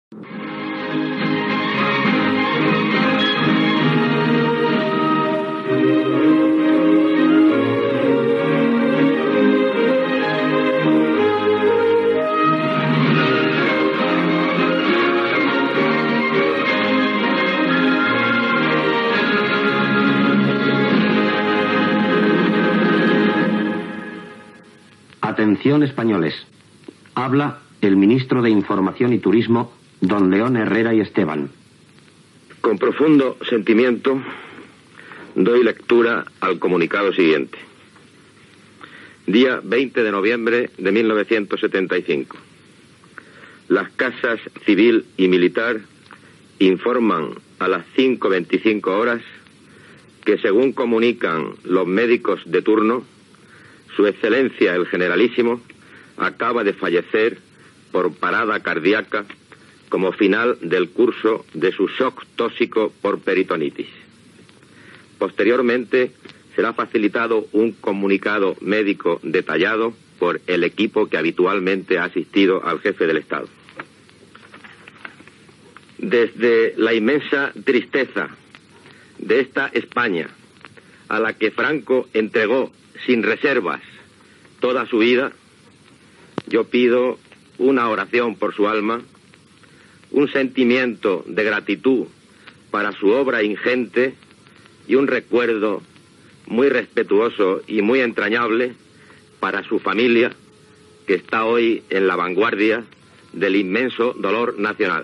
Sintonia de l'emissora, butlletí informatiu de les 6 del matí, el ministre d'informació i turisme León Herrera comunica la mort del "generalísimo" Francsico Franco, a les 5:25 hores de la matinada
Informatiu